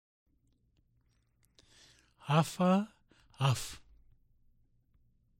1. Vowel contrasts
A. Listen to the difference between a and in the pairs of words below.
(Each example is given in both short and long forms.)